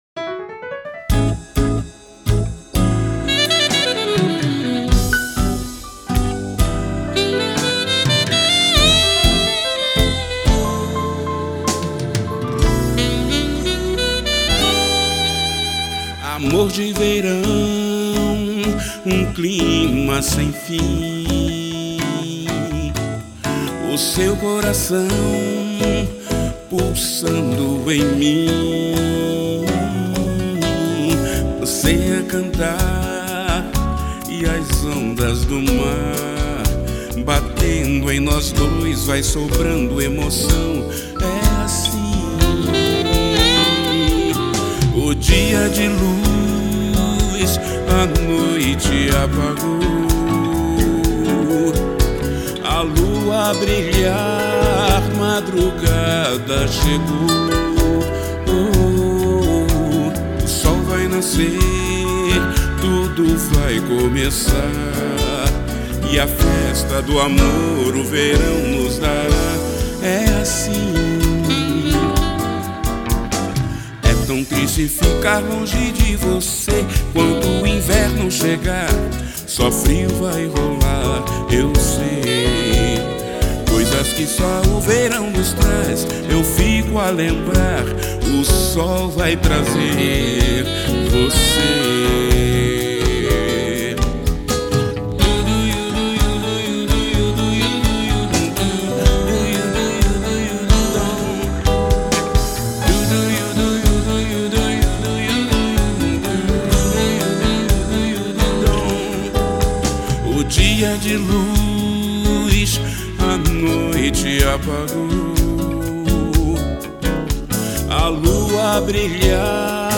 samba carioca
roda de samba